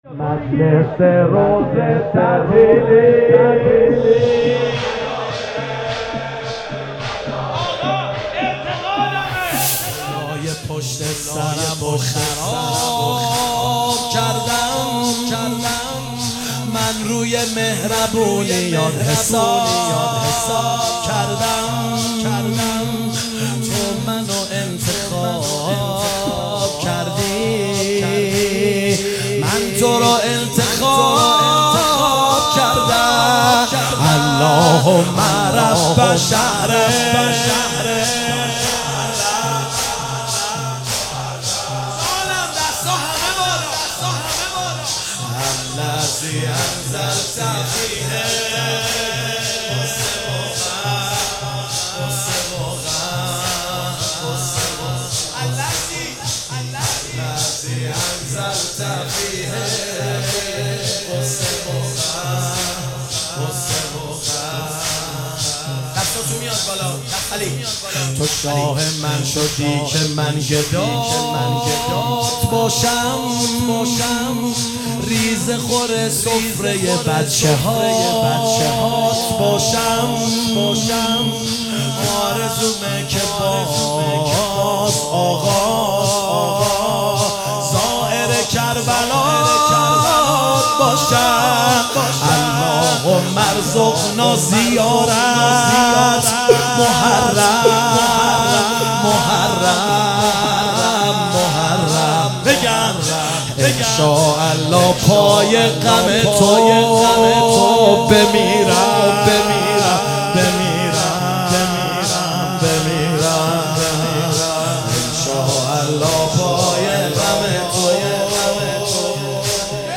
هیئت عشاق الرضا (ع) تهران |محرم 1399